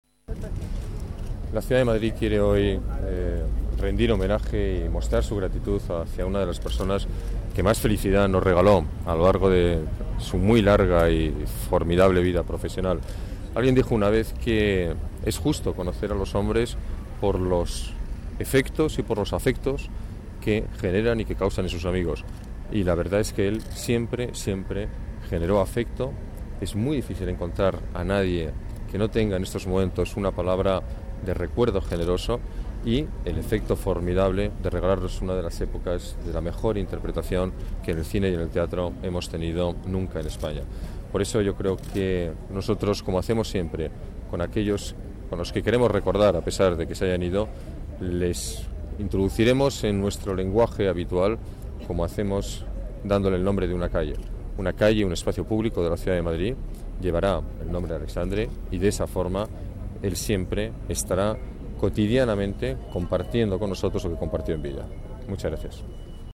El alcalde visita la capilla ardiente del actor en el Teatro Español
Nueva ventana:Declaraciones del Alcalde en recuerdo de Alexandre